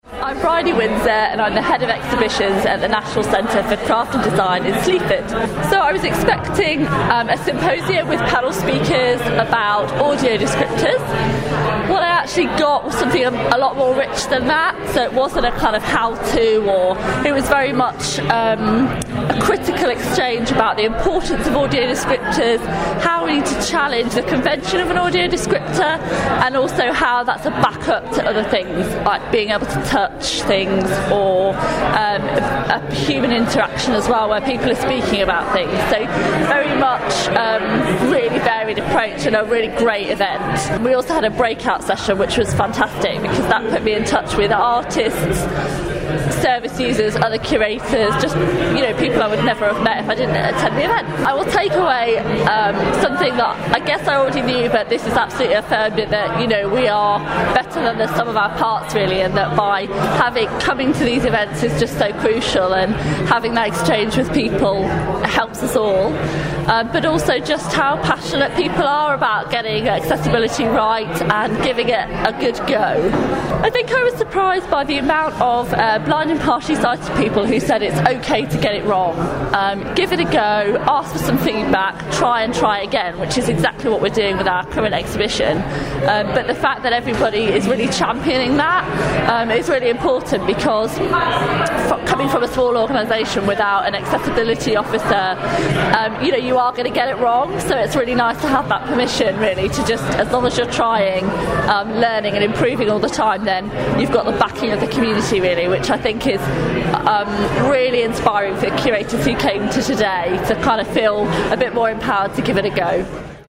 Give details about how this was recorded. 'Bridging the Gaps: Exploring the Link Between Art and Audio Description' symposium held at Tate Modern February 2017 hosted by Shape Arts and Tate Modern